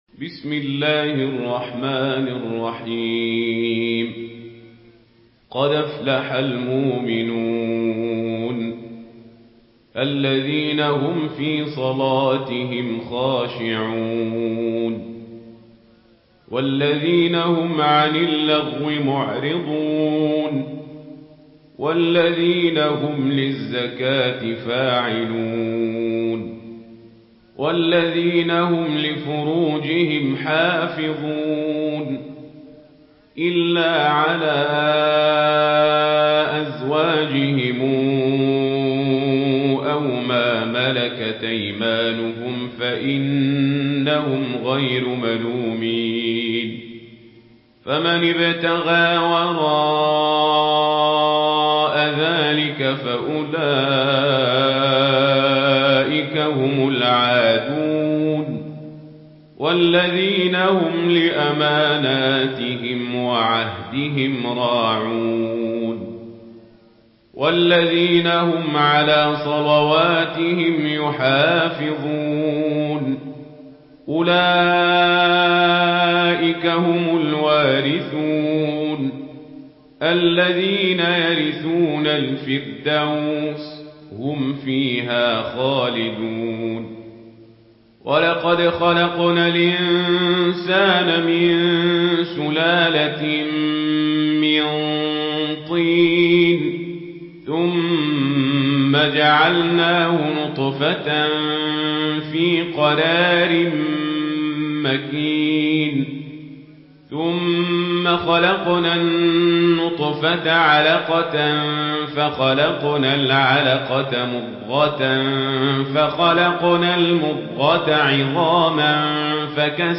سورة المؤمنون MP3 بصوت عمر القزابري برواية ورش عن نافع، استمع وحمّل التلاوة كاملة بصيغة MP3 عبر روابط مباشرة وسريعة على الجوال، مع إمكانية التحميل بجودات متعددة.
مرتل ورش عن نافع